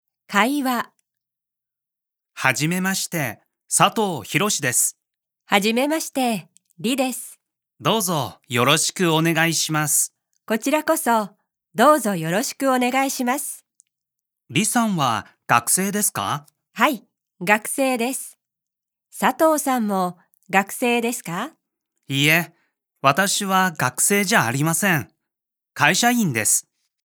2 全書採用教科書字體，MP3由日籍老師錄製
本書附上的有聲MP3是由專業日籍播音員錄製，讀者可以聽到最專業又正確的日文。